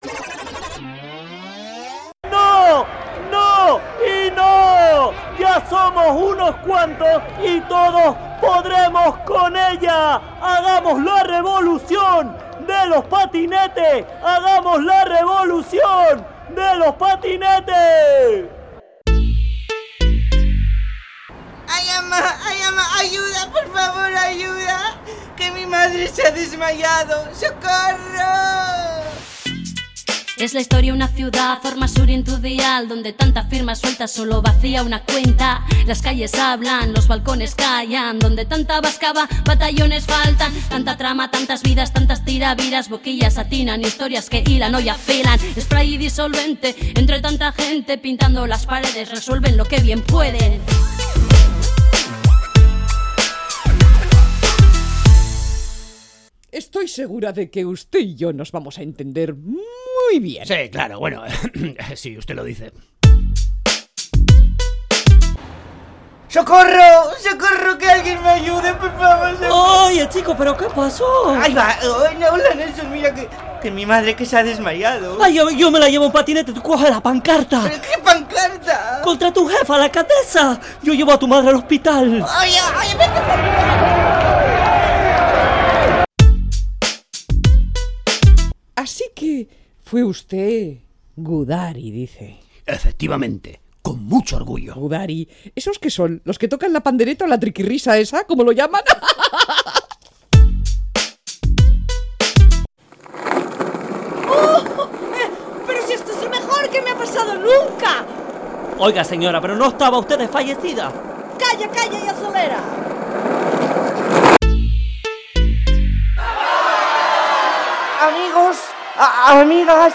Entrega número 13 de la Radio-Ficción “Spray & Disolvente”